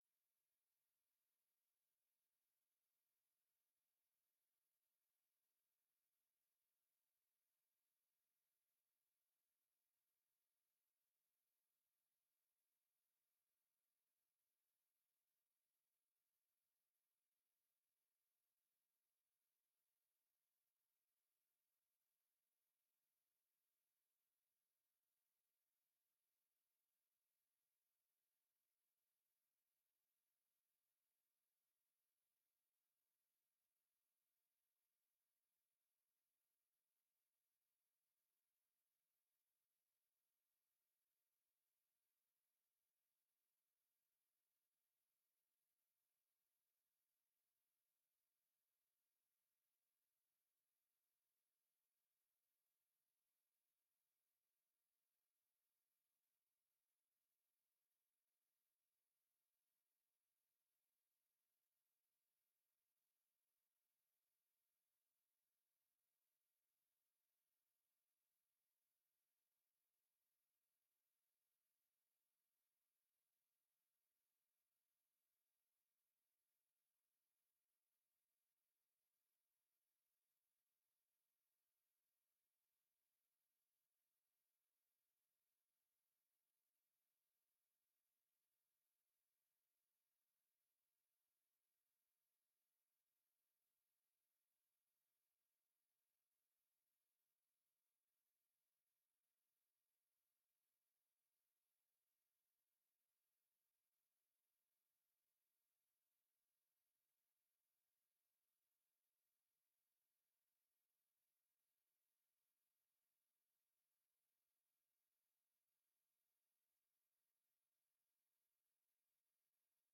Raadsvergadering 19 december 2024 20:00:00, Gemeente Ouder-Amstel